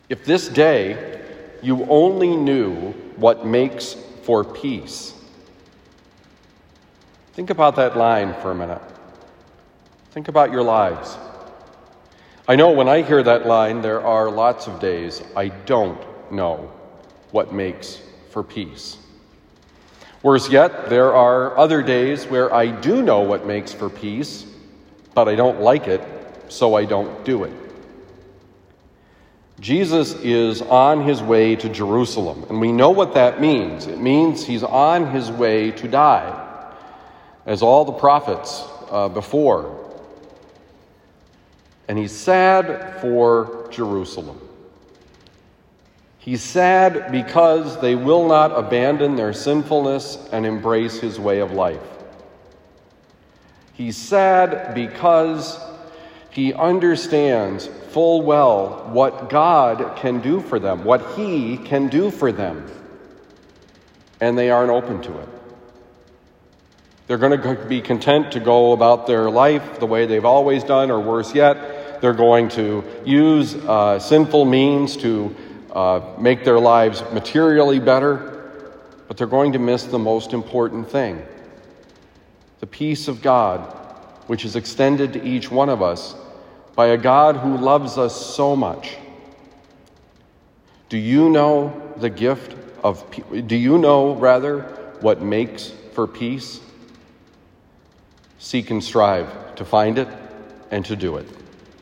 Homily for Thursday, November 18, 2021
Given at Christian Brothers College High School, Town and Country, Missouri.